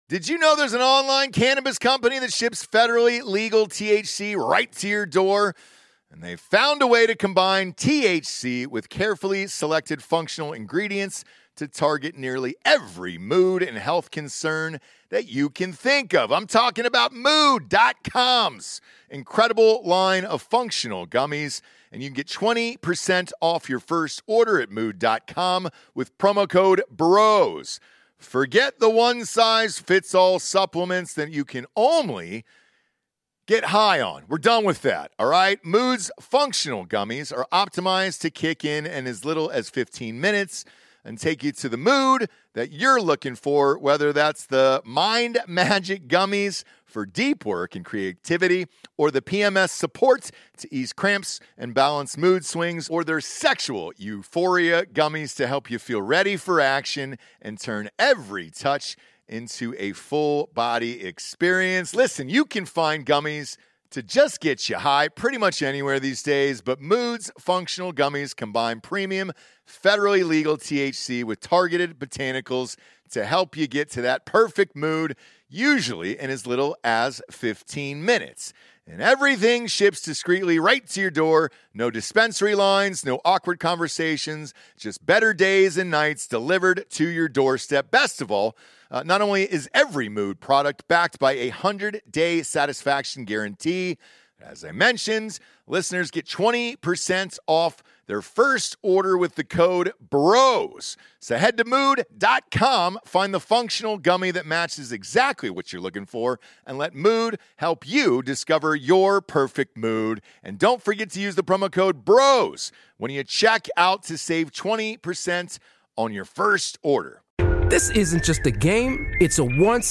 Well, we're going to do an interview today.